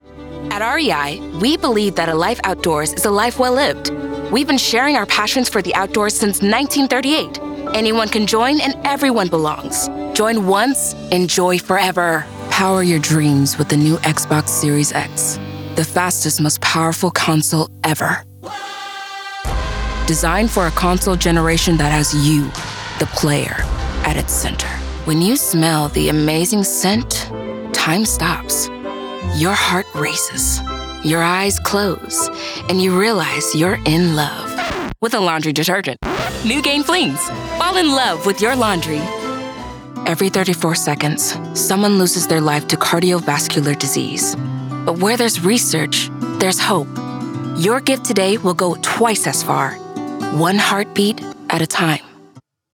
Pacific Northwest
Teen
Young Adult
Commercial